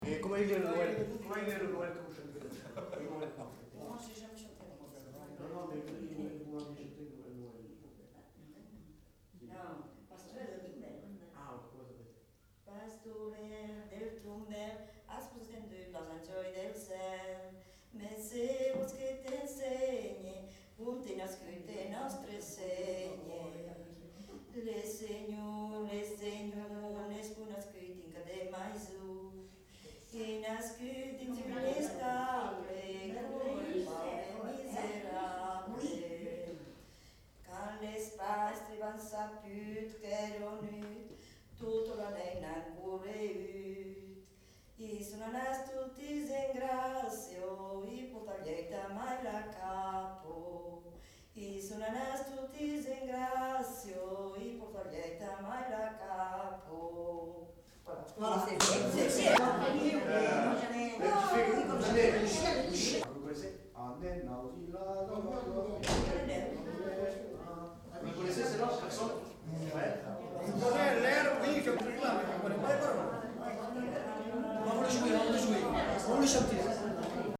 Aire culturelle : Lauragais
Genre : chant
Effectif : 1
Type de voix : voix de femme
Production du son : chanté
Classification : noël